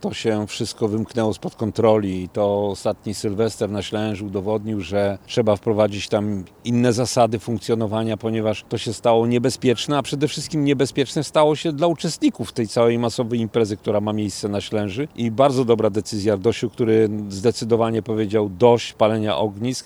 Jak podkreśla Mirosław Jarosz, burmistrz miasta i gminy Sobótka, decyzja ta jest odpowiedzią na rosnące zagrożenie związane z niekontrolowanymi zachowaniami turystów.
Sleza-1-burmistrz.mp3